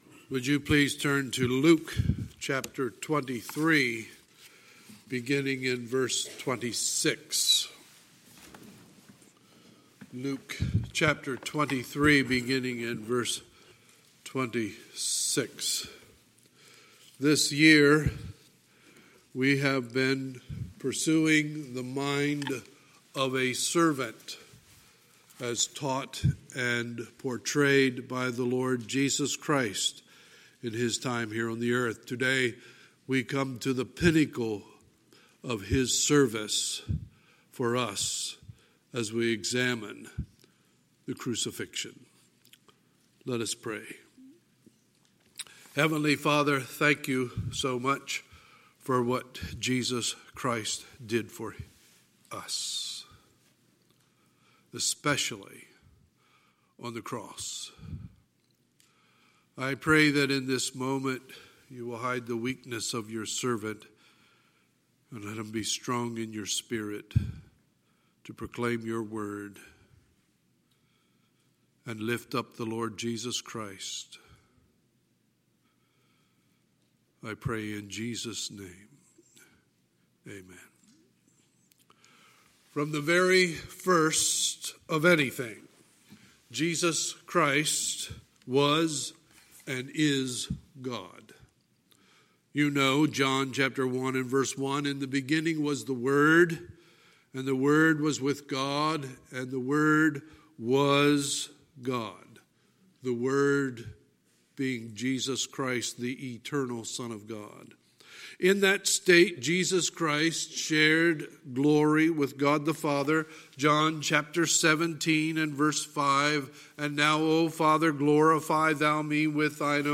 Sunday, November 17, 2019 – Sunday Morning Service